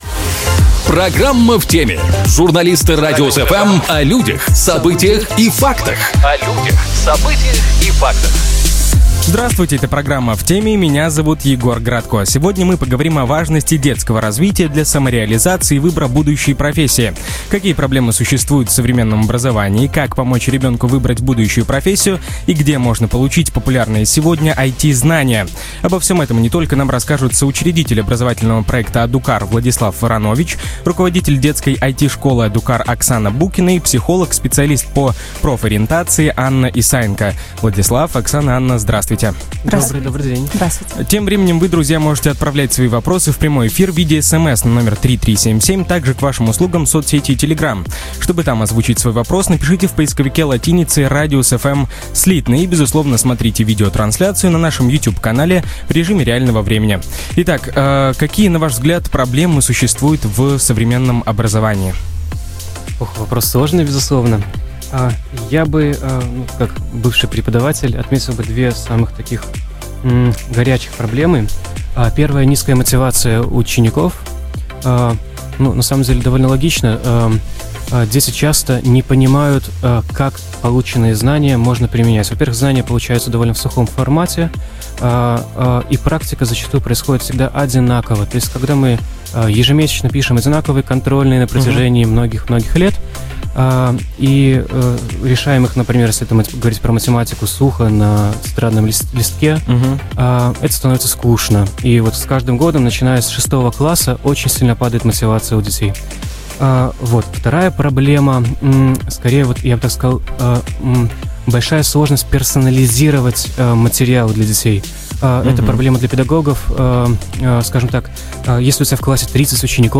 психолог, специалист по профориентации